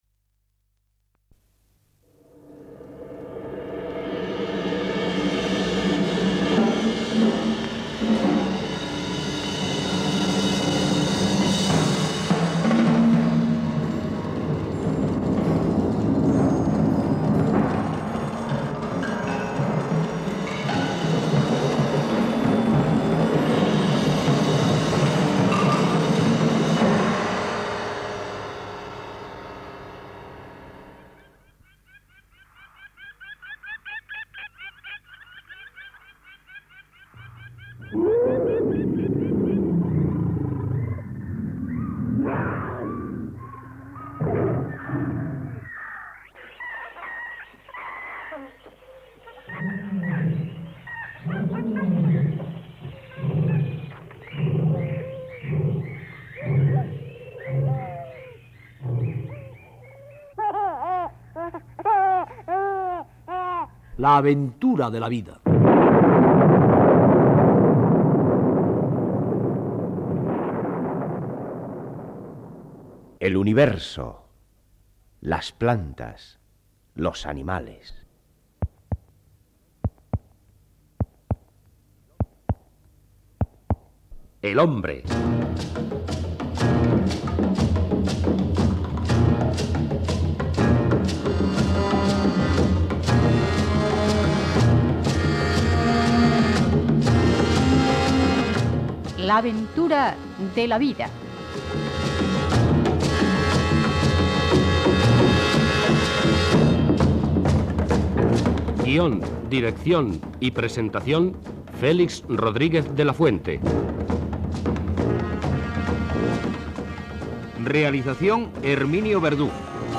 02e132aea0dff4a9c4a5c1f9877c2805eb049275.mp3 Títol Tercer Programa de RNE Emissora Tercer Programa de RNE Cadena RNE Titularitat Pública estatal Nom programa La aventura de la vida Descripció Careta del programa, amb els noms de l'equip. Desig d'un bon any, la importància de l'ecologia, crítica a la proposta de la instal·lació d'una urbanització a la Sierra de Gredos
Divulgació